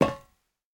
immersive-sounds / sound / footsteps / rails / rails-03.ogg
rails-03.ogg